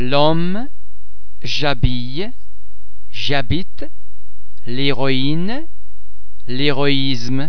Please be mindful of the fact that all the French sounds are produced with greater facial, throat and other phonatory muscle tension than any English sound.
Mostly silent: the [e] or [a] of the preceding word is dropped and replaced by an apostrophe